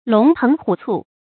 龍騰虎蹴 注音： ㄌㄨㄙˊ ㄊㄥˊ ㄏㄨˇ ㄘㄨˋ 讀音讀法： 意思解釋： 喻勇猛沖擊，勢不可當。